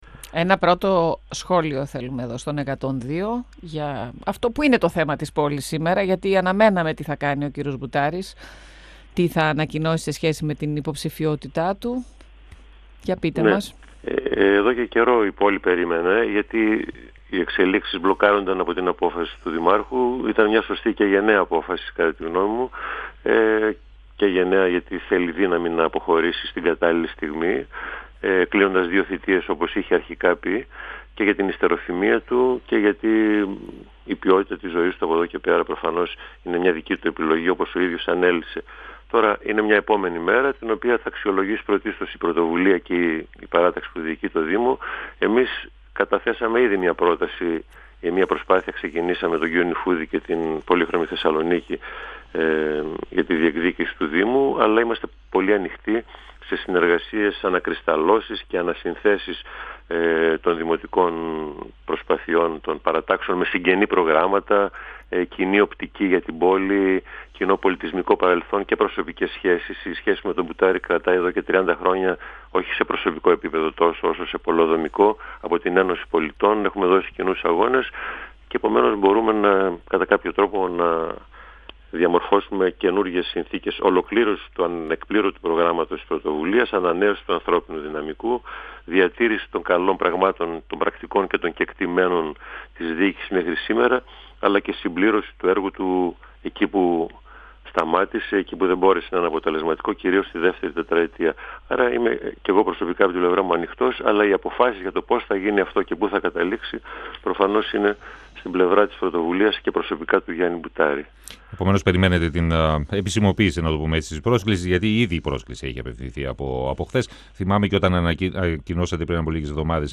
Για σωστή και γενναία απόφαση έκανε λόγο ο Σπύρος Βούγιας, μιλώντας στον 102FM της ΕΡΤ3 σχετικά με την απόφαση του δημάρχου Θεσσαλονίκης. Παράλληλα ο κ. δήλωσε ανοικτός σε συζητήσεις για το ενδεχόμενο συνεργασίας με την παράταξη της πλειοψηφίας στο δημοτικό συμβούλιο
Παράλληλα ο κ. δήλωσε ανοικτός σε συζητήσεις για το ενδεχόμενο συνεργασίας με την παράταξη της πλειοψηφίας στο δημοτικό συμβούλιο 102FM Συνεντεύξεις ΕΡΤ3